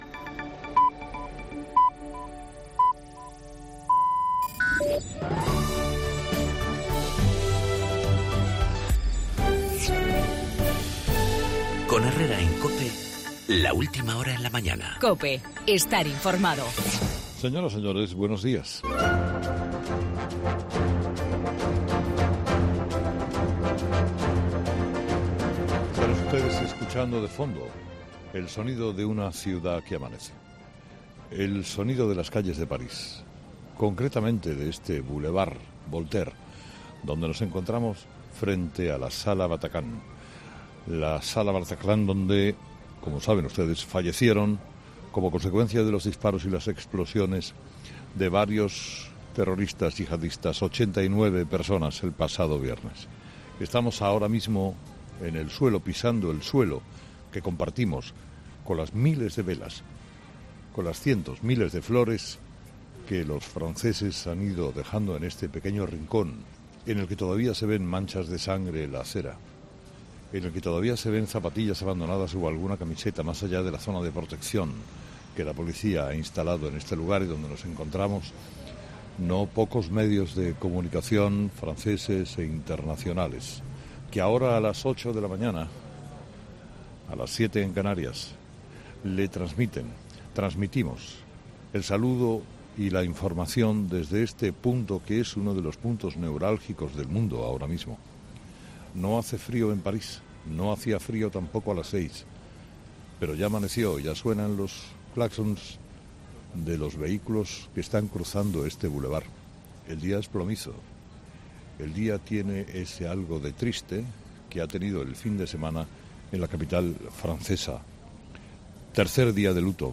El comunicador apuntaba entonces desde la misma puerta de la sala Bataclan que “el objetivo de los terroristas, forrados literalmente de explosivos y tornillos, era el de explotar dos de sus cuerpos dentro del Stade de France donde se estaba disputando el Francia – Alemania para causar una estampida. Mientras tanto, un tercero esperaría en la salida para hacer lo propio y arrasar el máximo de vidas”.